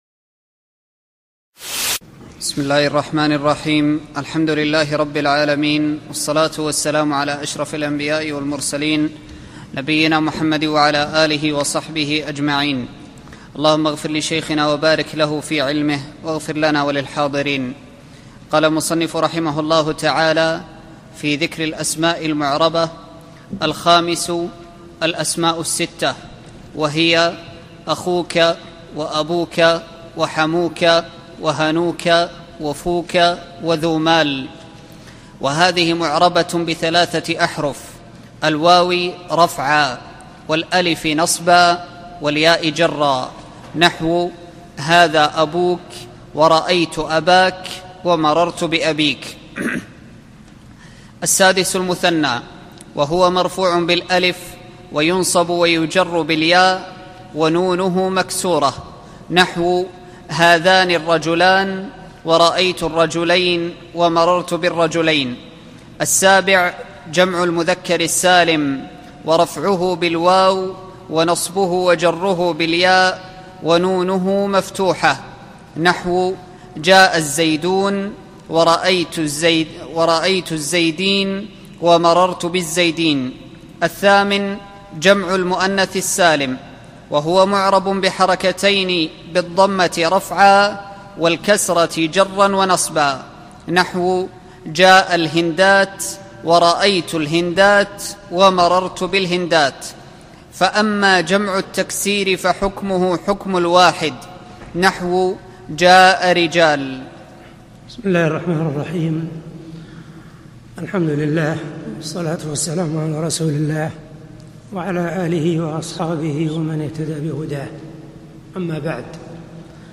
الدورة العلمية 15 المقامة في جامع عبداللطيف آل الشيخ في المدينة النبوية لعام 1435
الدرس الثاني